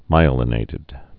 (mīə-lə-nātĭd)